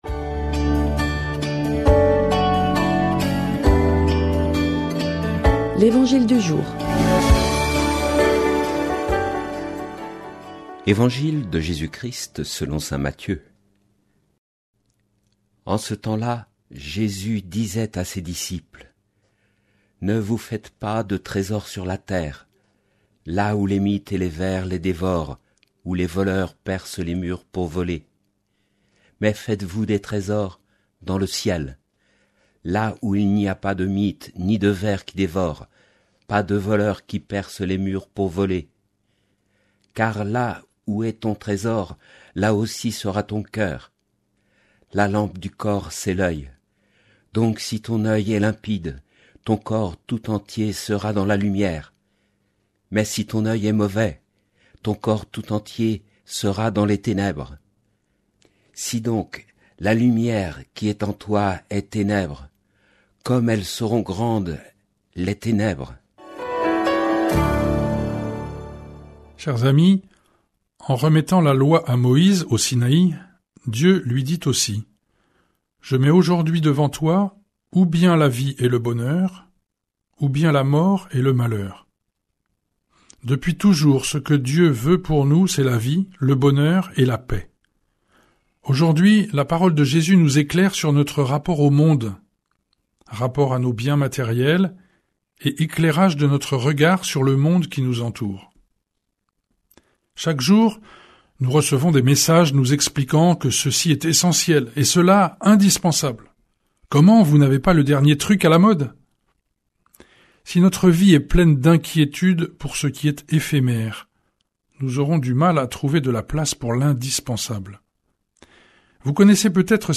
Commentaire d'évangile
Commentaire diffusé sur Fidélité, radio chrétienne de Nantes.